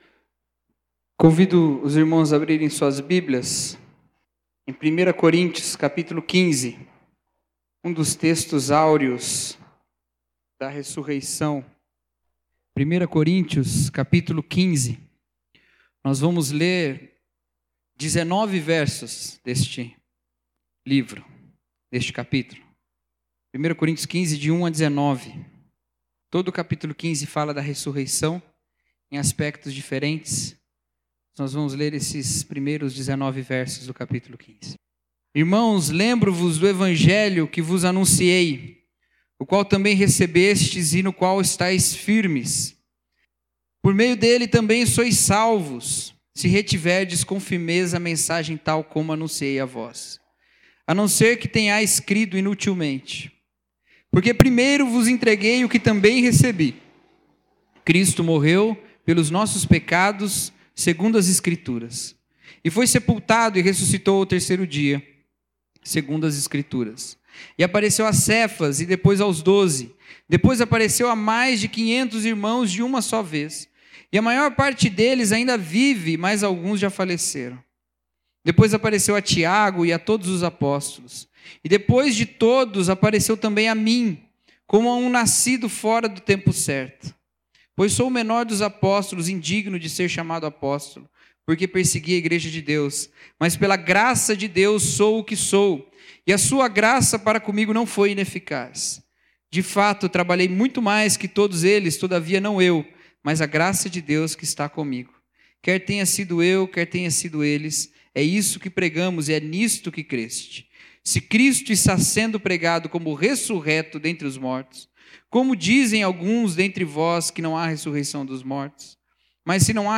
Mensagem: A Mensagem da Ressureição